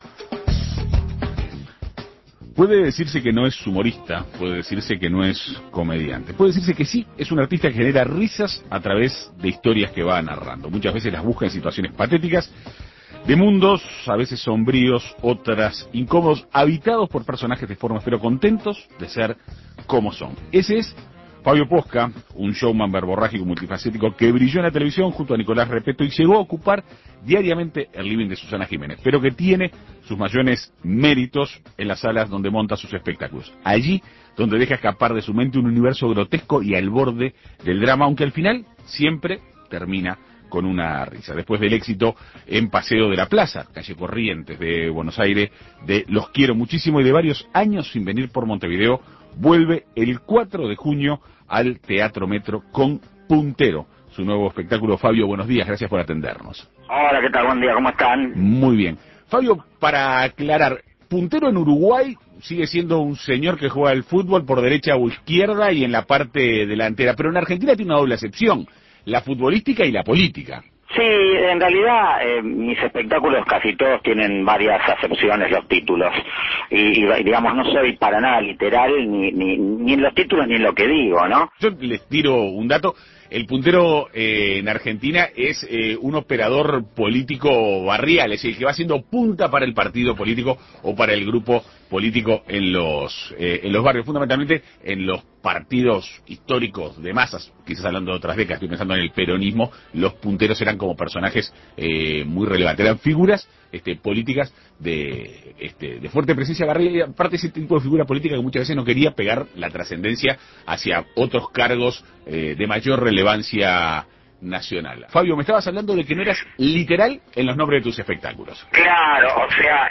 Después del éxito de "Los quiero Muchísimo", vuelve el 4 de junio al Teatro Metro con "Puntero". En Perspectiva Segunda Mañana dialogó con Posca sobre los detalles de su futura presentación.